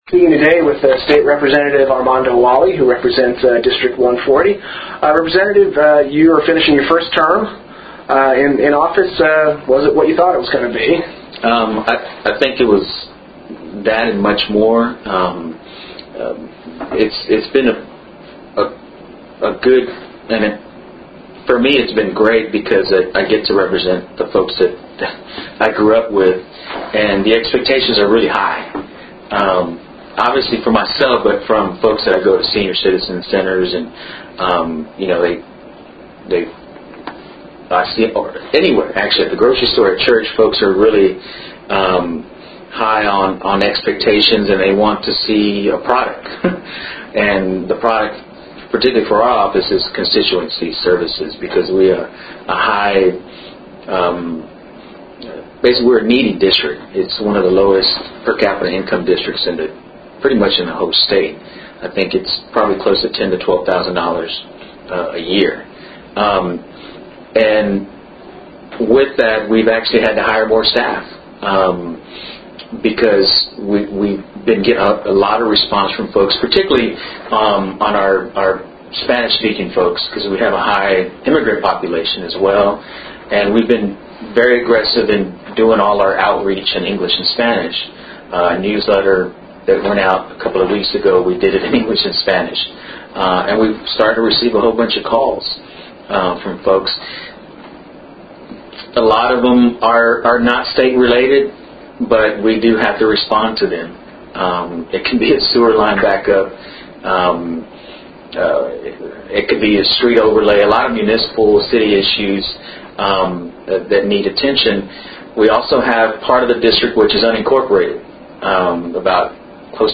Interview with State Rep. Armando Walle | Off the Kuff